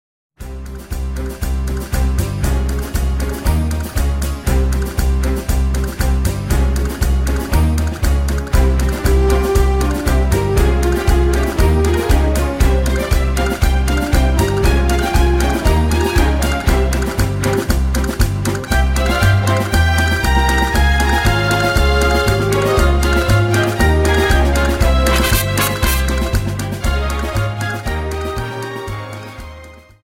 Dance: Paso Doble 59